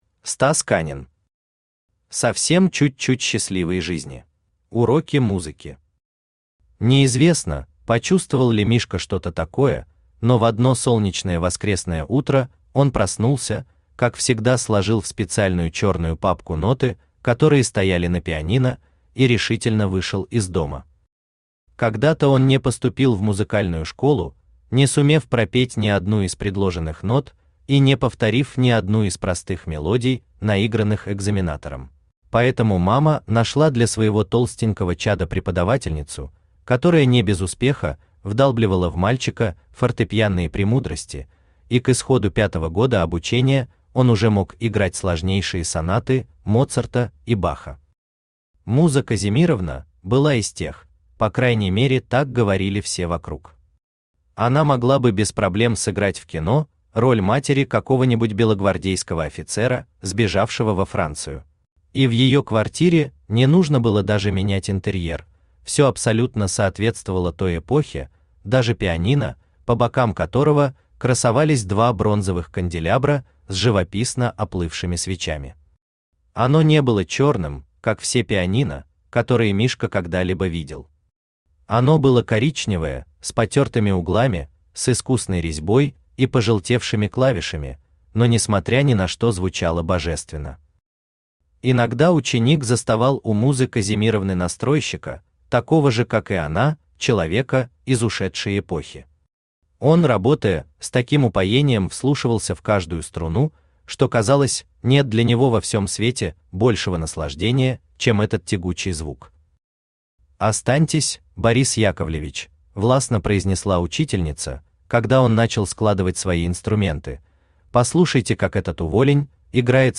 Аудиокнига Совсем чуть-чуть счастливой жизни | Библиотека аудиокниг
Aудиокнига Совсем чуть-чуть счастливой жизни Автор Стас Канин Читает аудиокнигу Авточтец ЛитРес.